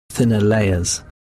Here is UCL’s Prof. Mark Miodownik saying thinner layers with an unsmoothed sequence ɛjə in layers: